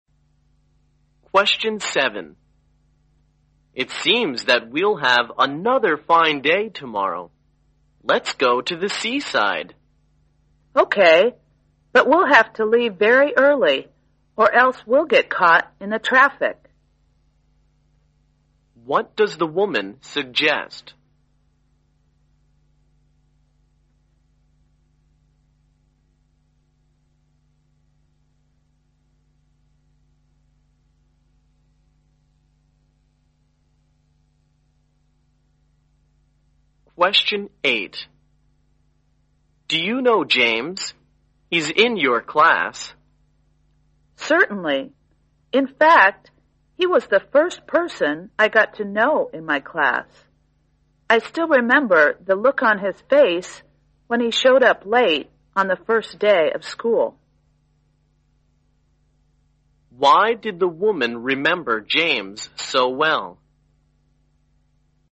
在线英语听力室127的听力文件下载,英语四级听力-短对话-在线英语听力室